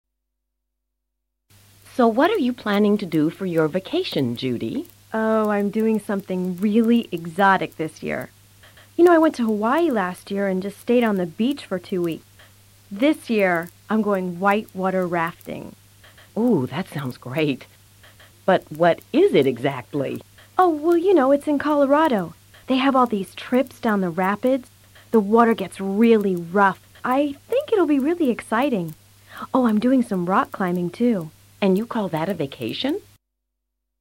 Esta sección, dividida en tres diálogos, presenta diferentes formas de vacacionar.